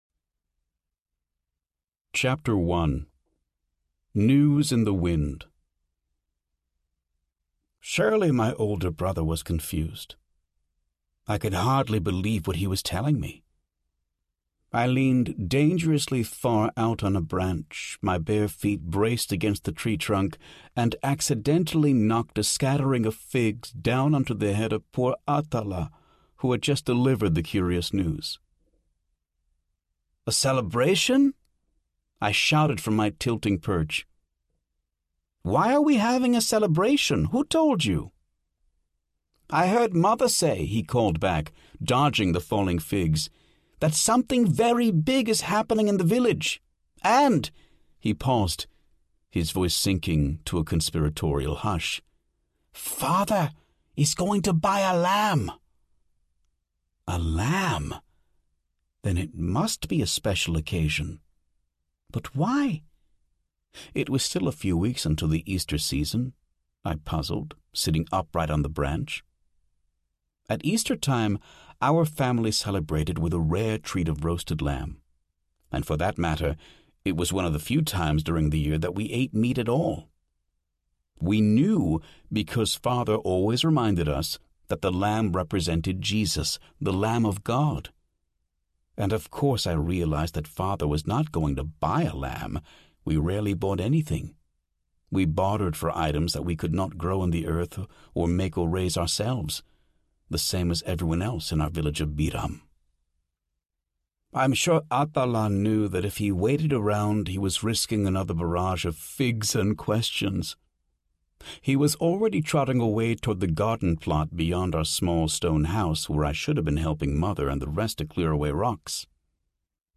Blood Brothers Audiobook
Narrator